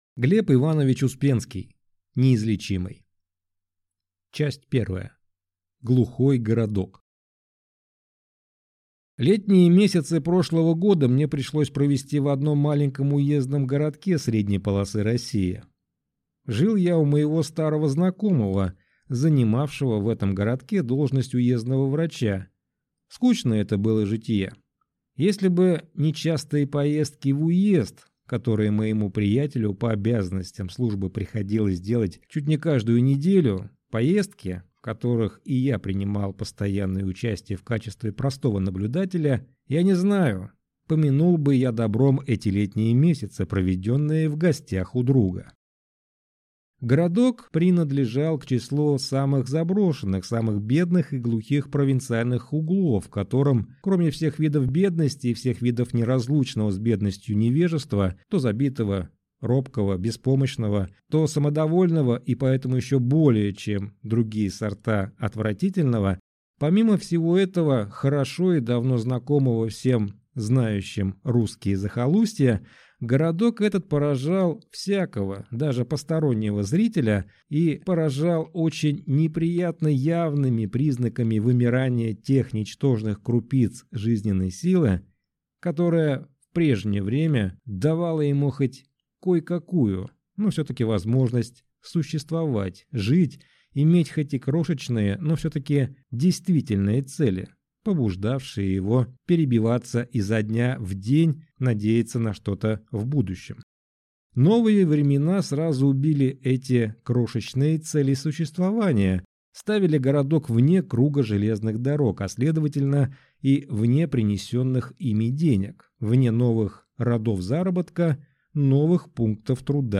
Аудиокнига Неизлечимый | Библиотека аудиокниг
Прослушать и бесплатно скачать фрагмент аудиокниги